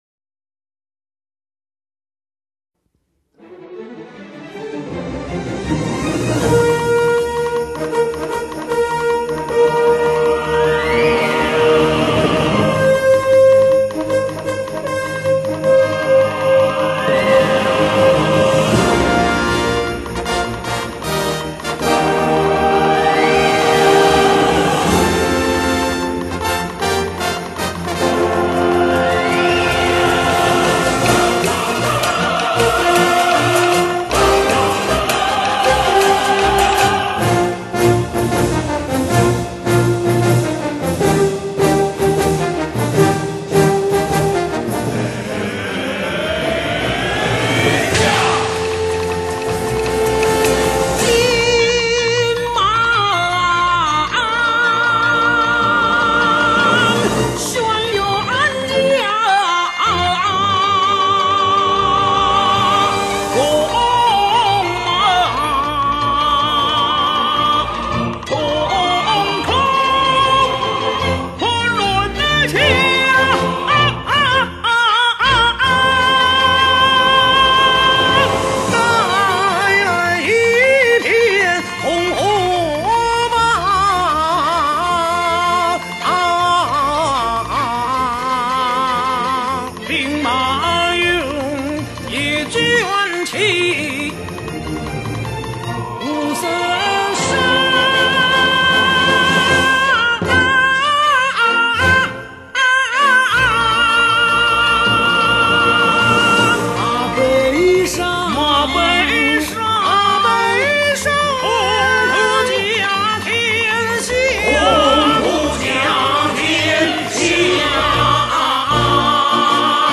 light]大型管弦伴京剧唱腔